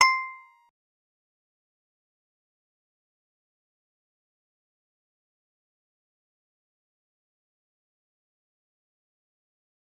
G_Musicbox-C6-pp.wav